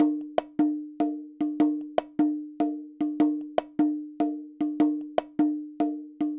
Bongo 02.wav